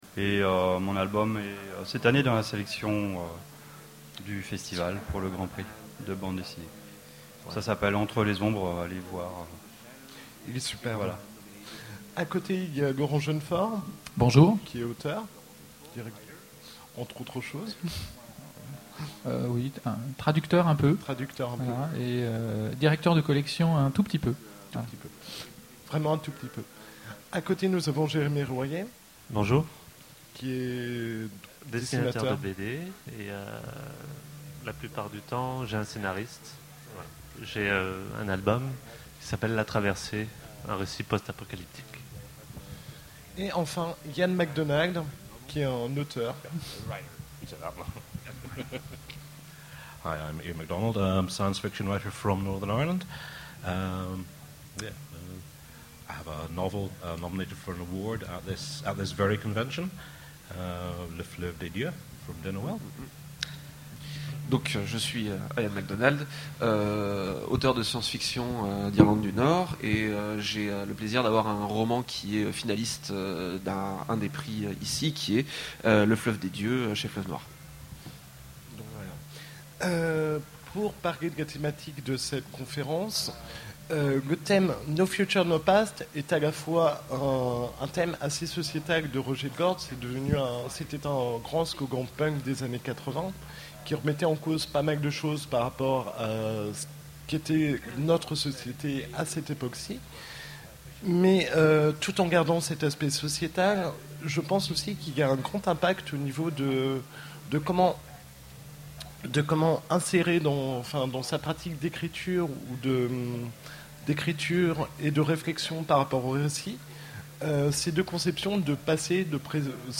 Utopiales 2011: Conférence No futur ! No past !